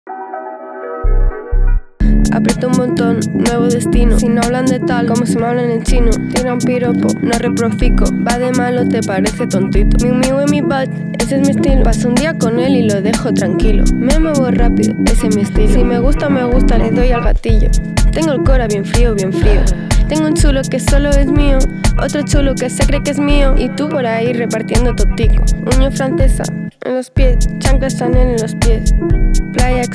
Indie Rock Alternative
Жанр: Рок / Альтернатива